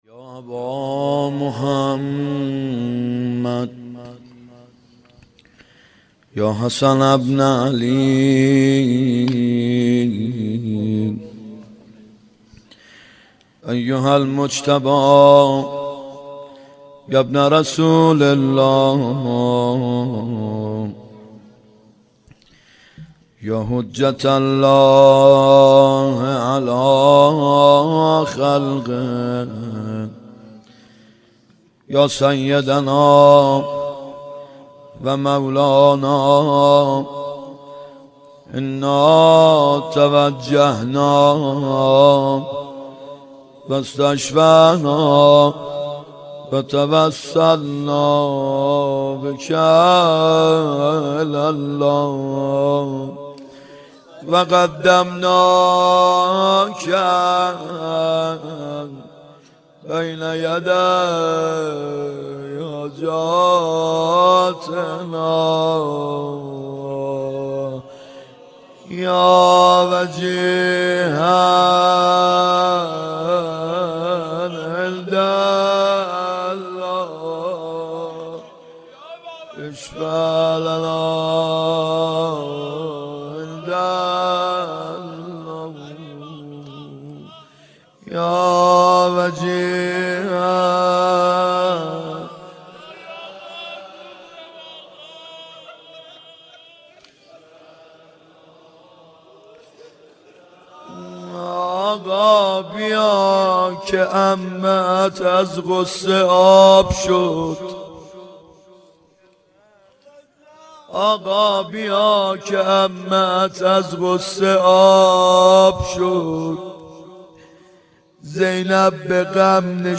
محرم 90 ( هیأت یامهدی عج)